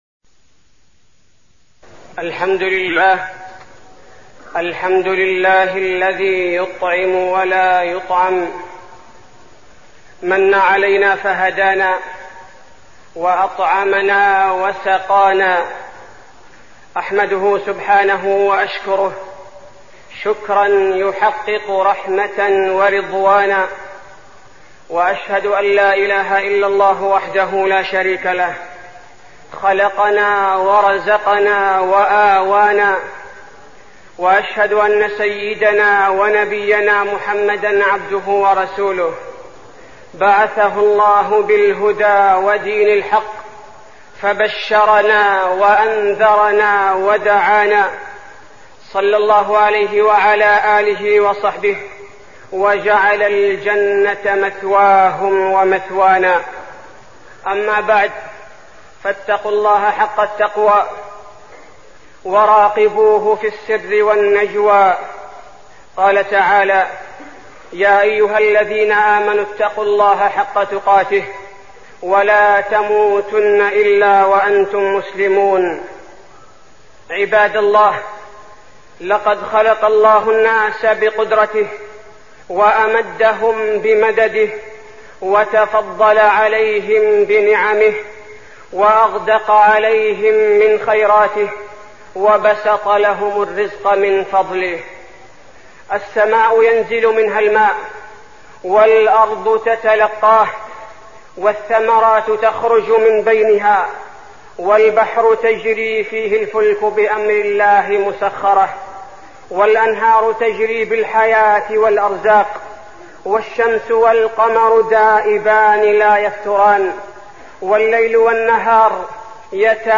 تاريخ النشر ١٩ جمادى الأولى ١٤١٦ هـ المكان: المسجد النبوي الشيخ: فضيلة الشيخ عبدالباري الثبيتي فضيلة الشيخ عبدالباري الثبيتي شكر الله تعالى على نعمه